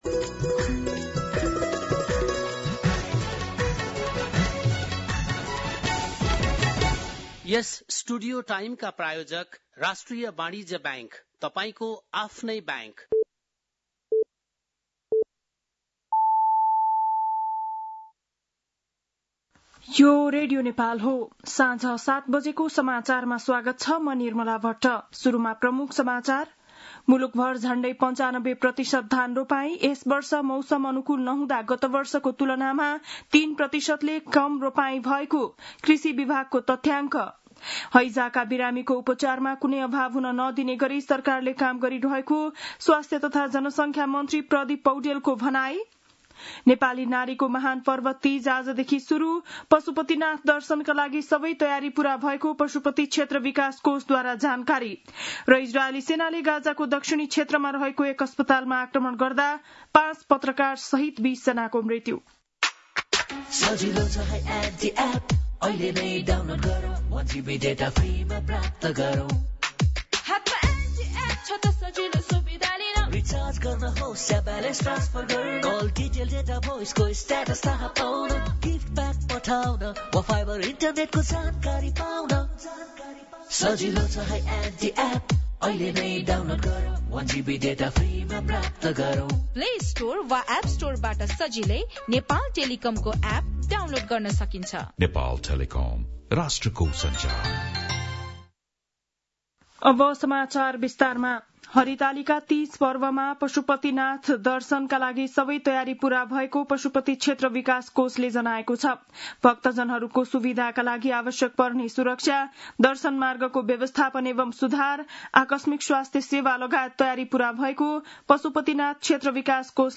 बेलुकी ७ बजेको नेपाली समाचार : ९ भदौ , २०८२
7-pm-nepali-news-5-09.mp3